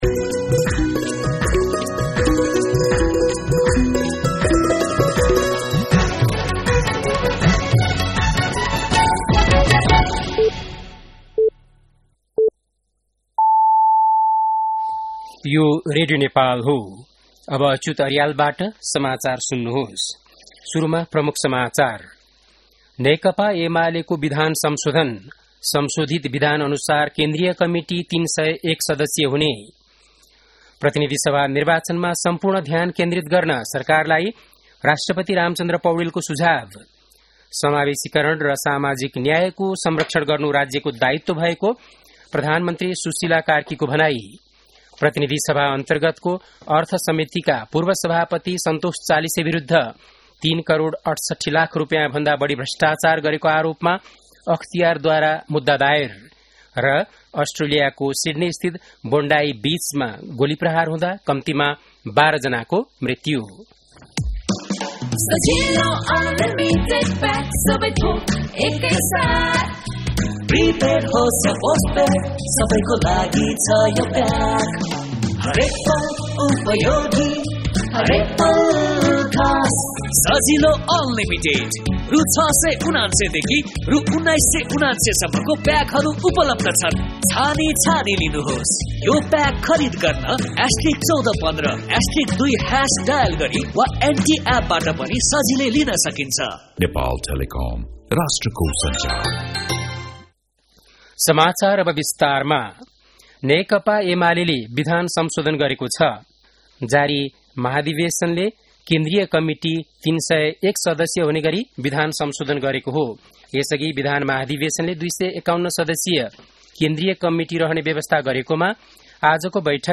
बेलुकी ७ बजेको नेपाली समाचार : २८ मंसिर , २०८२
7-pm-news-.mp3